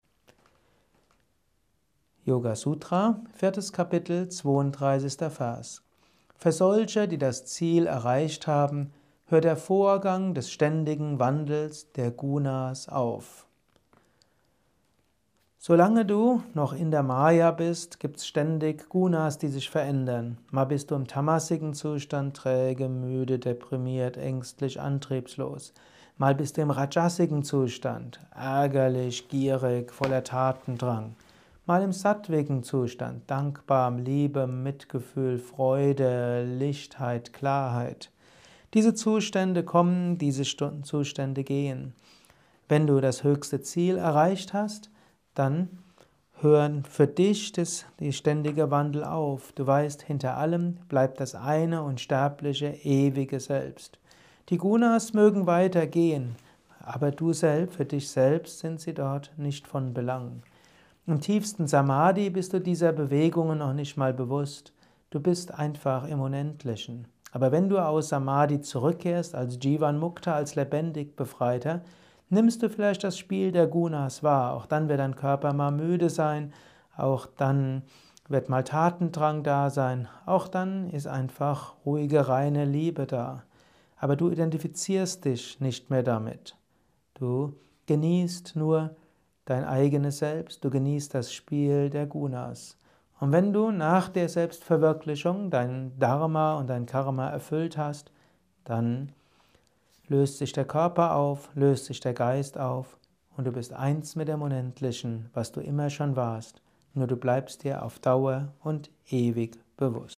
gehalten nach einer Meditation im Yoga Vidya Ashram Bad Meinberg.